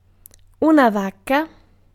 Ääntäminen
US : IPA : [kaʊ]